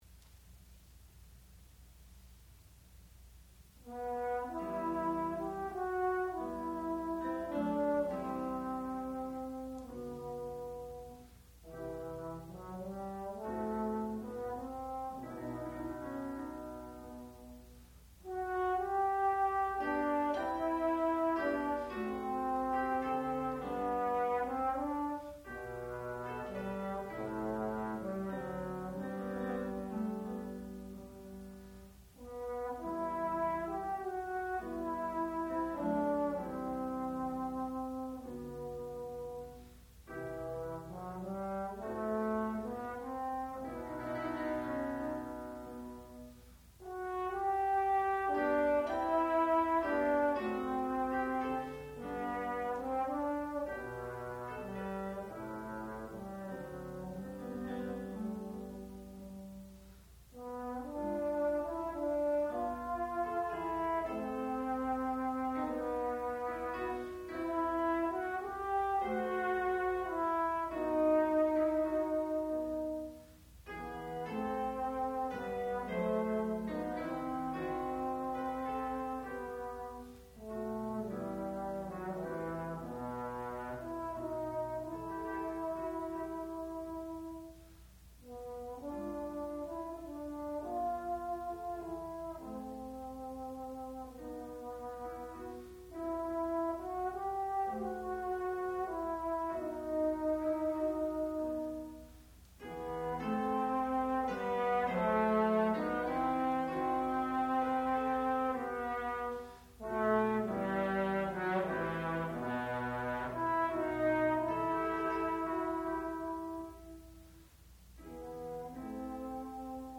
sound recording-musical
classical music
bass trombone
piano
Graduate Recital